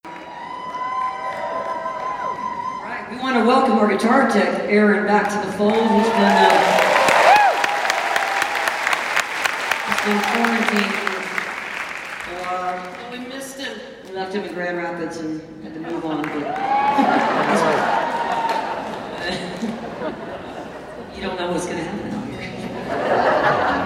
07. talking with the crowd (0:23)